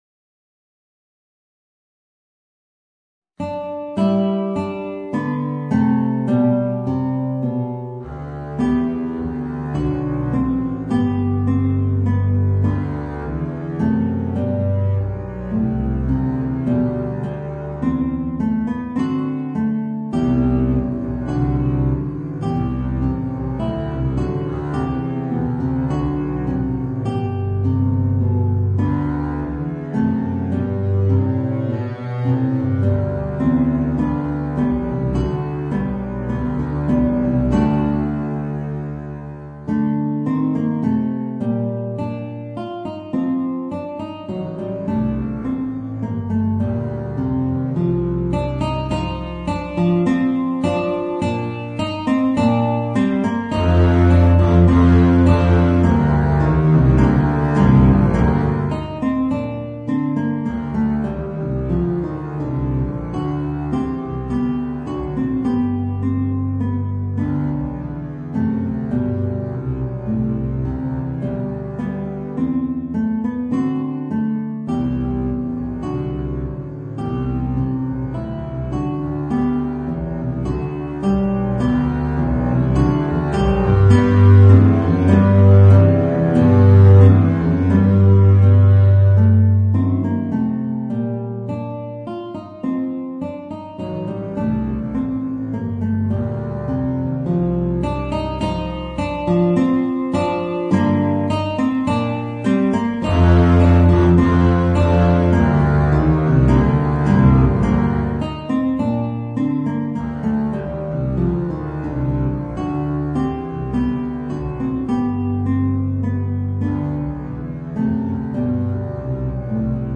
Voicing: Contrabass and Guitar